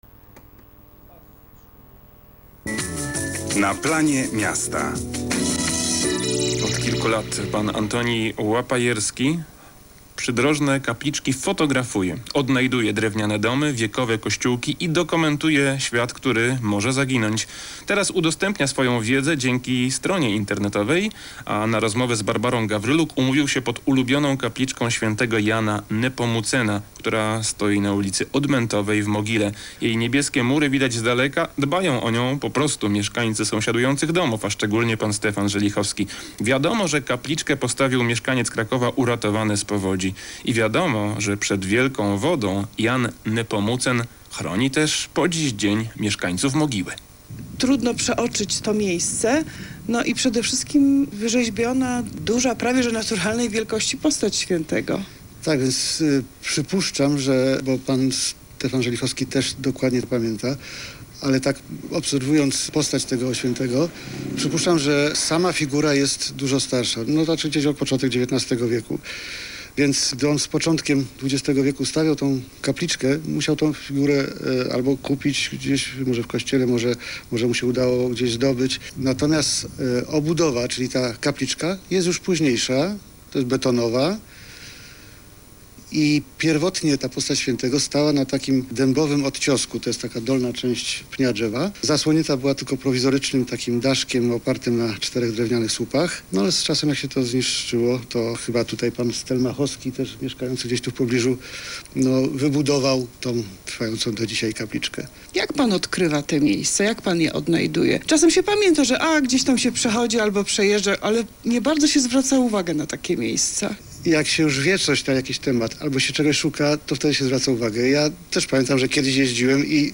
Radio Kraków Audycja radiowa - Grudzień 2004